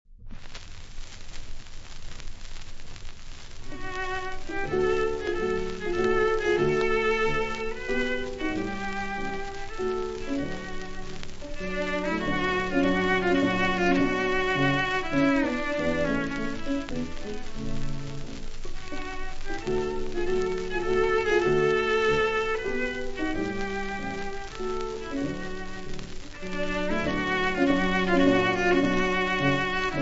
Personaggi e interpreti: pianoforte ; Schulhoff, Ervin. violoncello ; Casals, Pablo